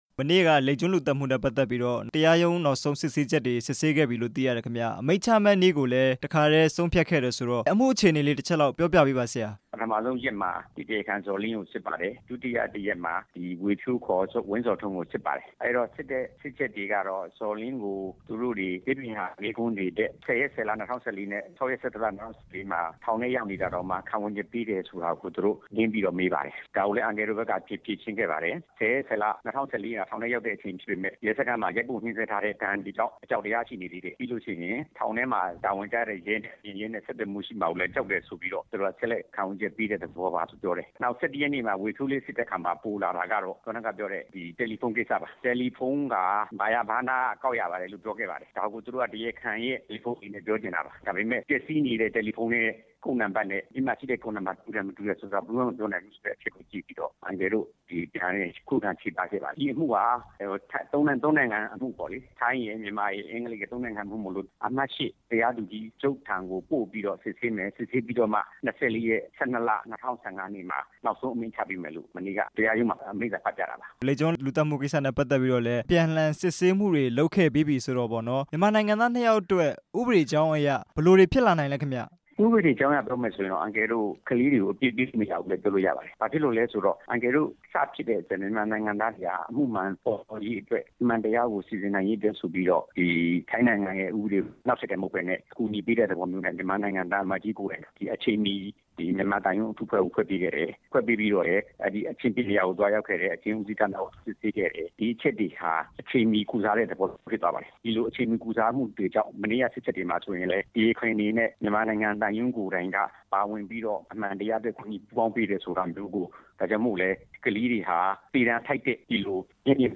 ခိုတောင်းကျွန်းအမှု ဒီဇင်ဘာလမှာ အမိန့်ချမယ့်အကြောင်း မေးမြန်းချက်